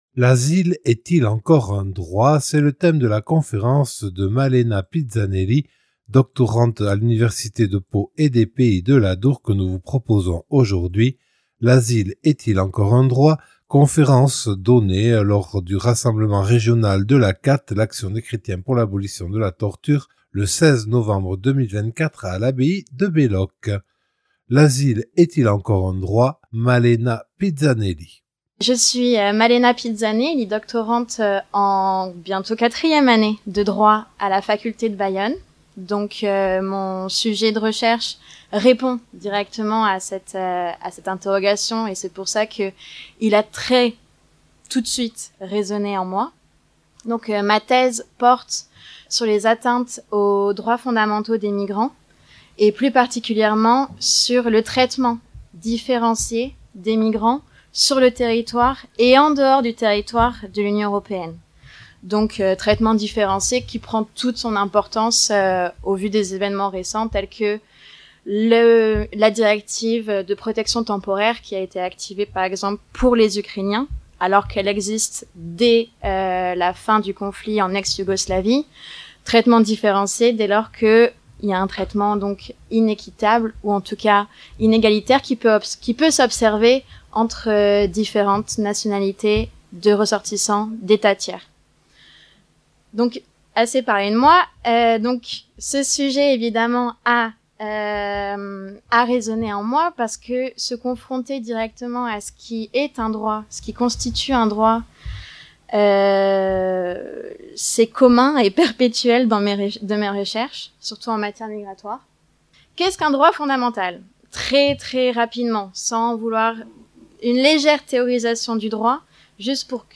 Enregistrée lors du rassemblement régional ACAT Pyrénées-Ouest à l’abbaye de Belloc le 16 novembre 2024.